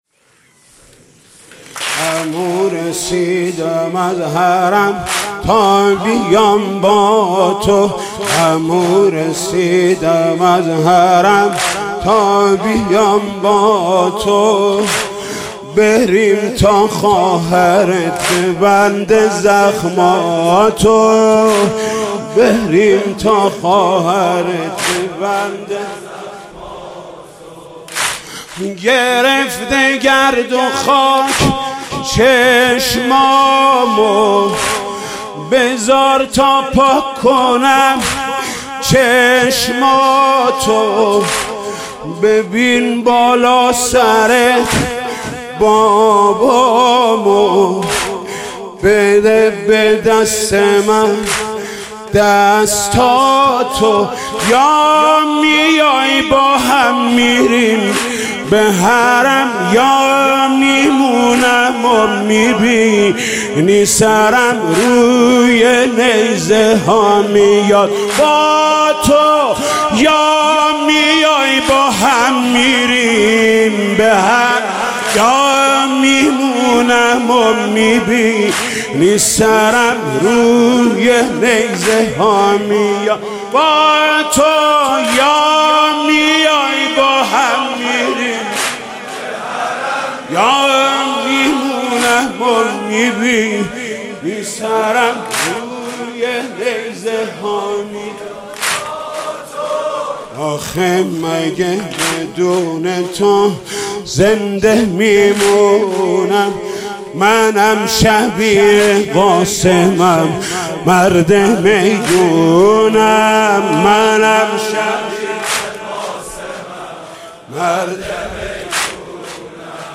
آموریم و همسرش اینگونه منچستر را ترک کردند / فیلم برچسب‌ها: حضرت عبدالله ابن الحسن شب پنجم محرم مداحی واحد زیبا ویژه محرم حاج محمود کریمی دیدگاه‌ها (5 دیدگاه) برای ارسال دیدگاه وارد شوید.